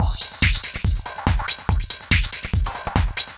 Index of /musicradar/uk-garage-samples/142bpm Lines n Loops/Beats
GA_BeatAFilter142-12.wav